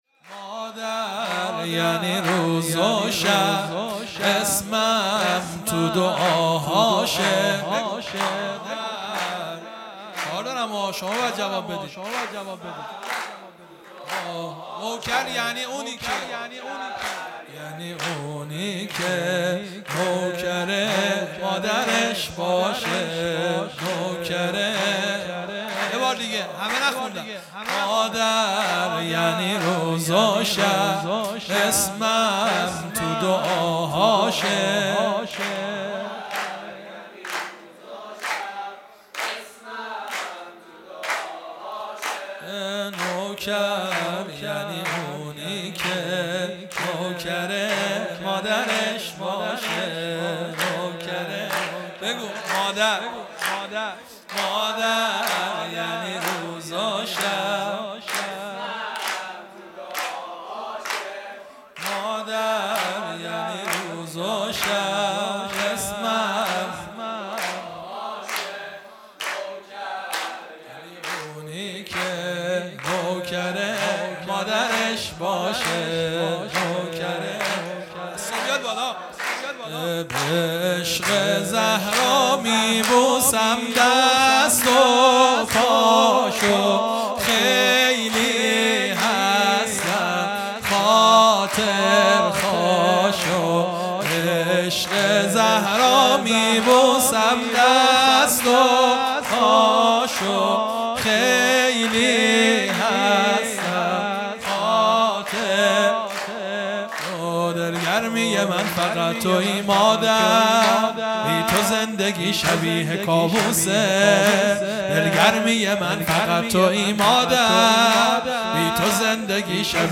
خیمه گاه - هیئت بچه های فاطمه (س) - سرود | مادر یعنی روز و شب اسمم تو دعاهاشه | 30 دی 1400
جلسۀ هفتگی | ولادت حضرت زهرا(س)